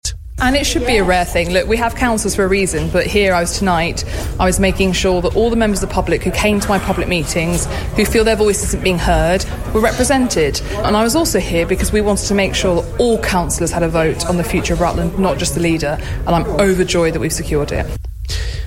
Rutland and Stamford's MP Alicia Kearns took the unusual step of speaking in the meeting at Catmos House last night, she says, to ensure all voices will be heard.